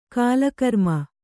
♪ kālakarma